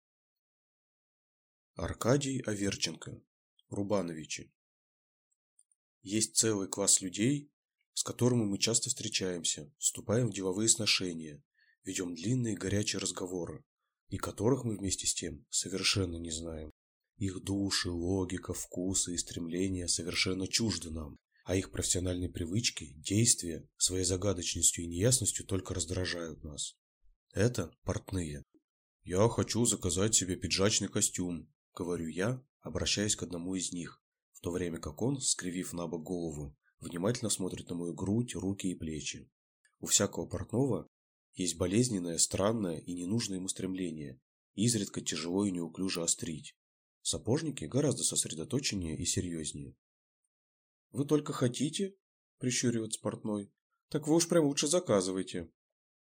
Аудиокнига Рубановичи | Библиотека аудиокниг